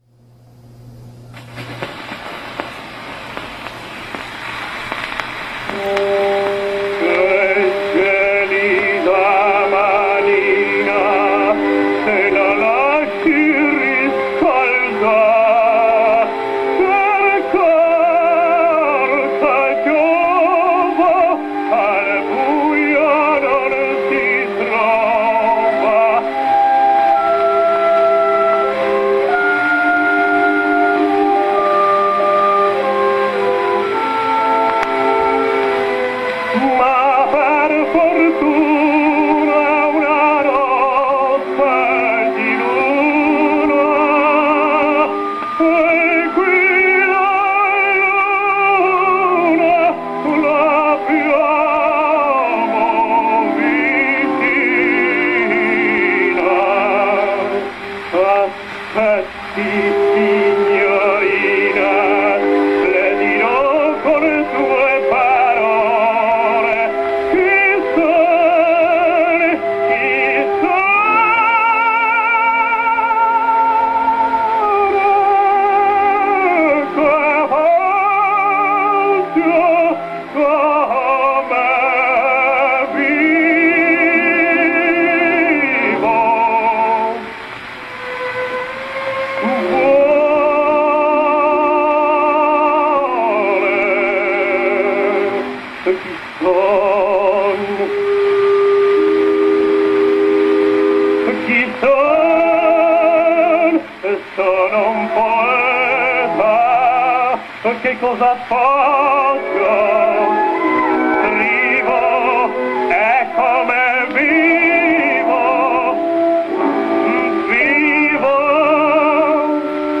tenore